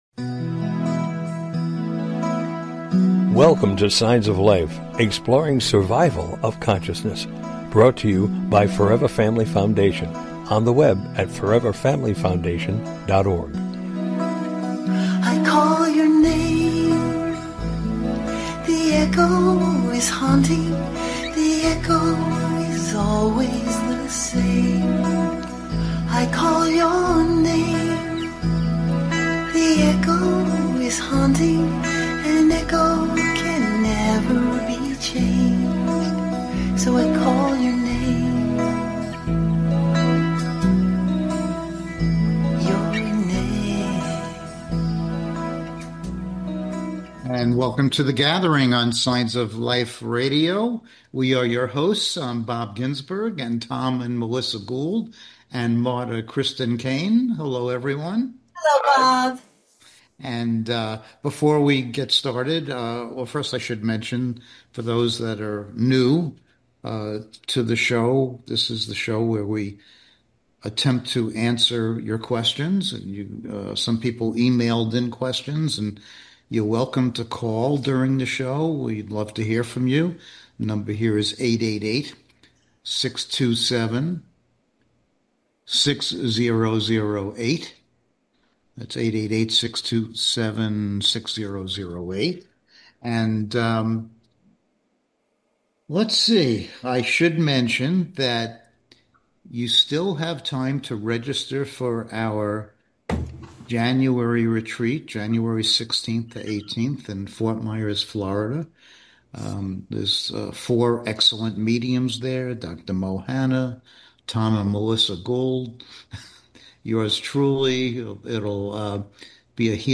Join us this evening for an engaging live discussion on life after death and consciousness!
Tonight our panel will be addressing questions from our listeners that pertain to all matters related to life after death, consciousness, spirituality, etc.